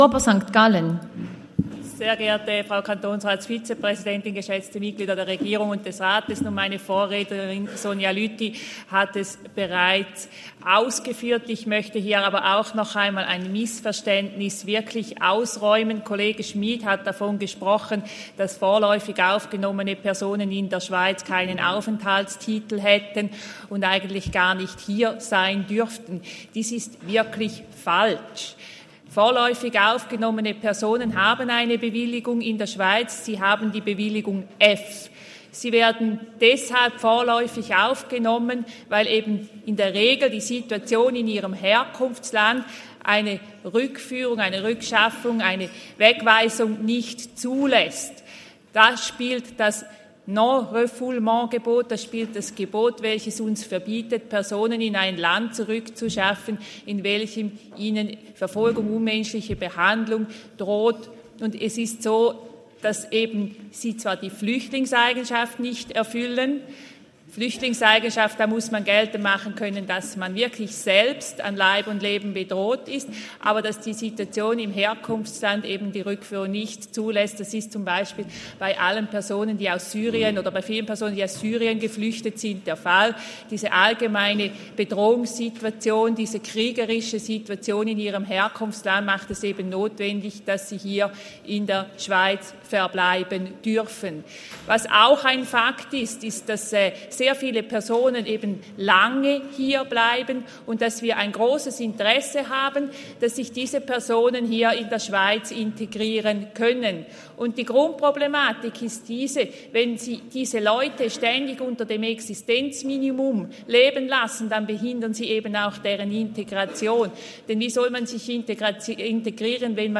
Session des Kantonsrates vom 13. bis 15. Februar 2023, Frühjahrssession
Surber-St.Gallen: Auf die Motion ist einzutreten.